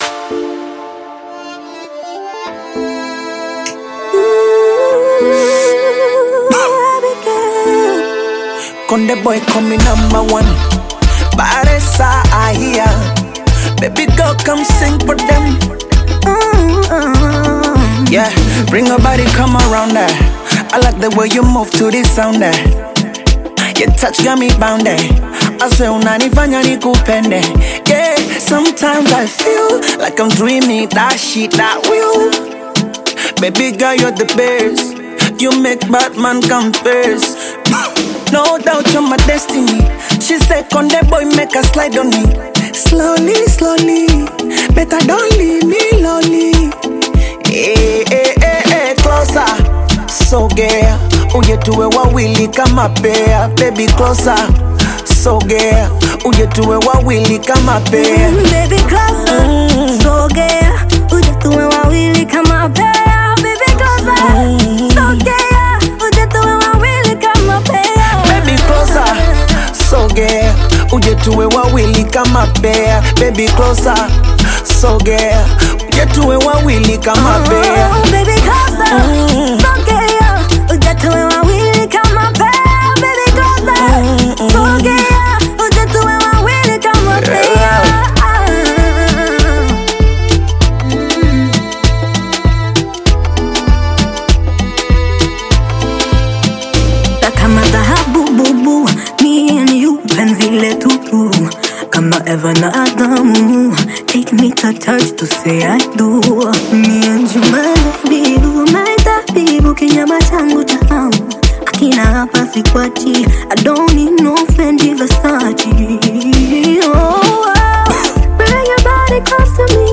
Tanzanian bongo flava
African Music